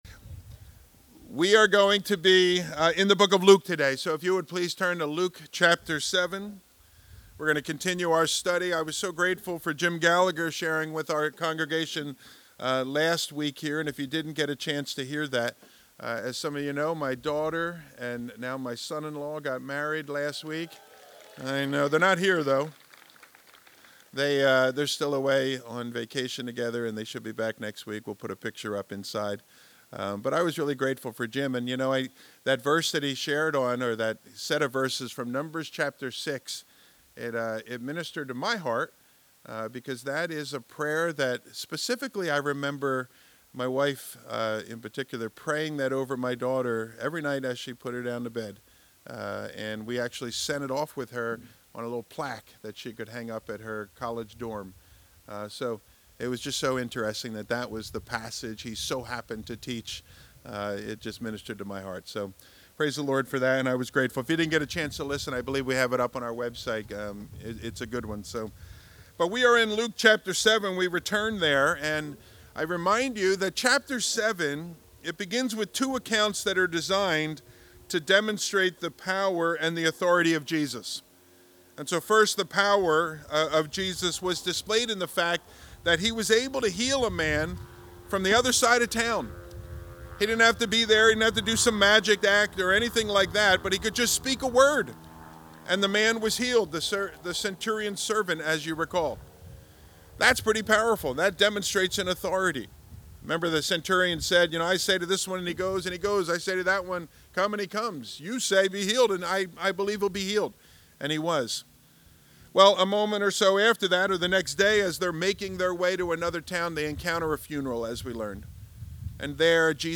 Luke 6:17-26 – Calvary Mercer Sermon Podcast – Lyssna här